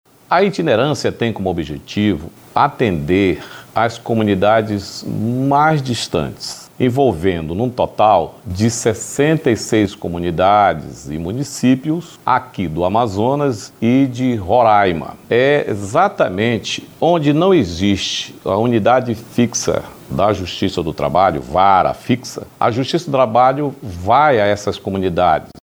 SONORA-2-DESEMBARGADOR.mp3